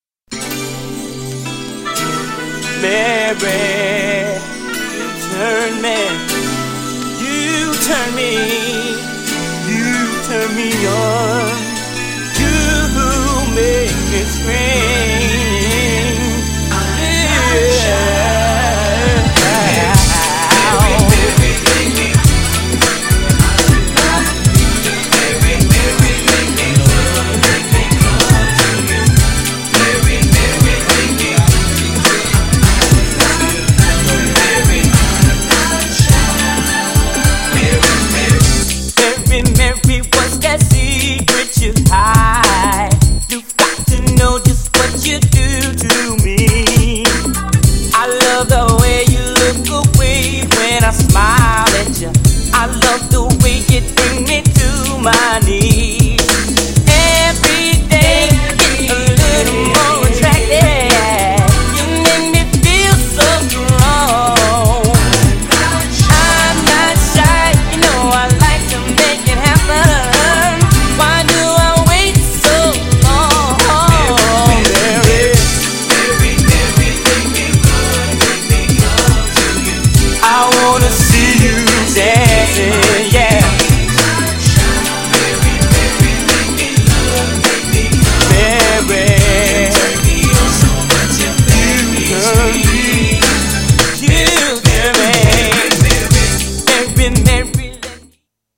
GENRE R&B
BPM 71〜75BPM
MIDナンバー # NEW_JACK
ちょいハネ系 # ハートウォームナンバー # 男性コーラスR&B